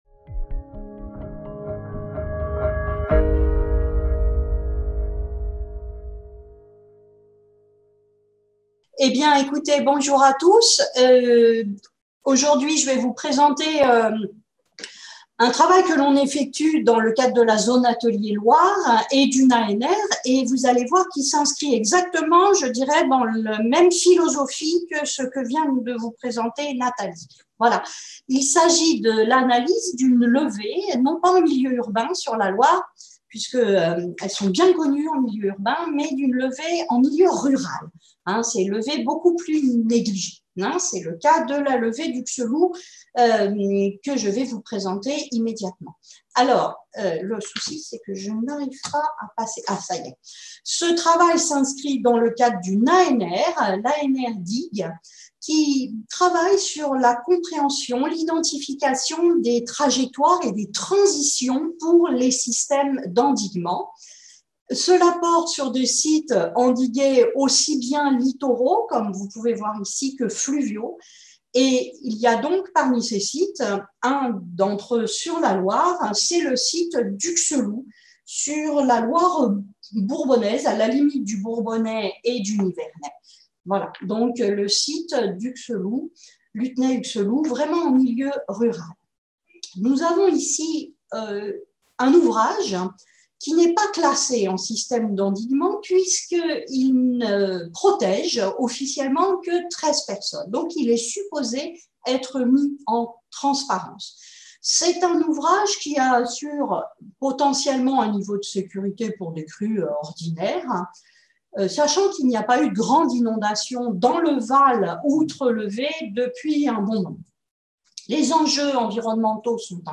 5e colloque des Zones Ateliers – CNRS 2000-2020, 20 ans de recherche du Réseau des Zones Ateliers